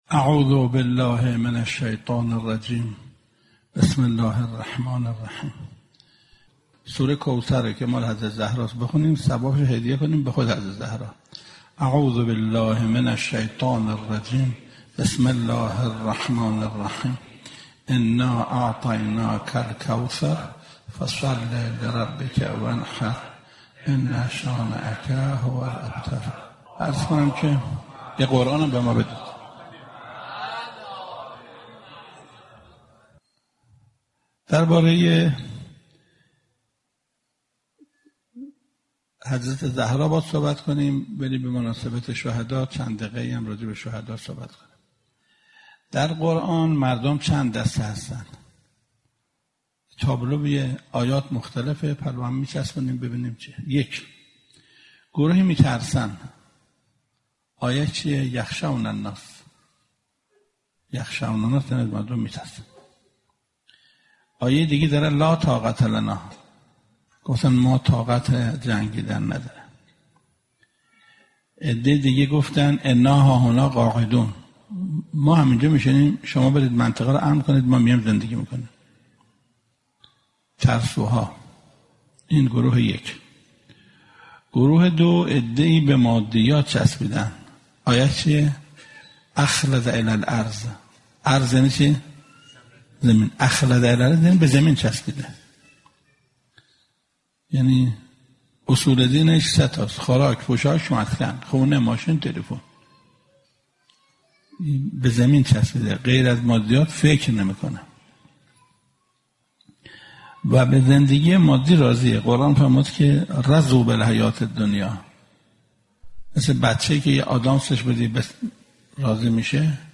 سخنرانی حجت‌الاسلام محسن قرائتی با موضوع مقام شهدا و شهید حاج قاسم سلیمانی